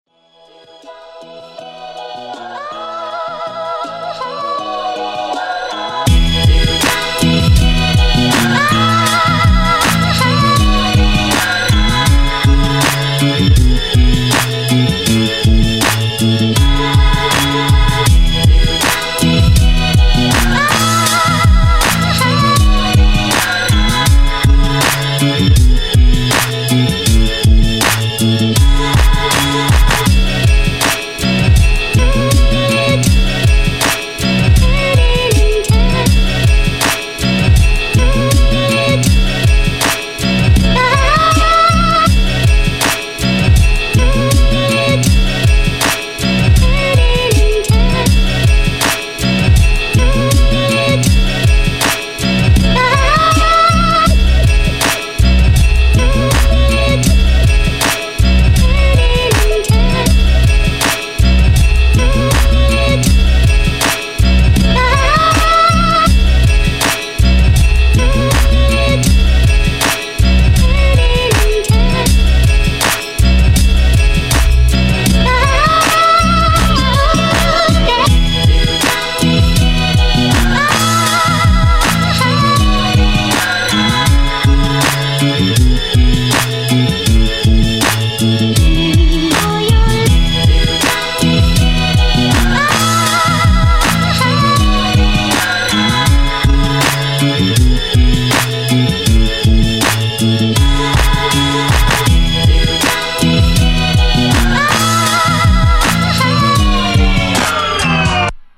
Submission for the Beat Competition in St. Louis Tonight.
The vocal samples are a perfect fit.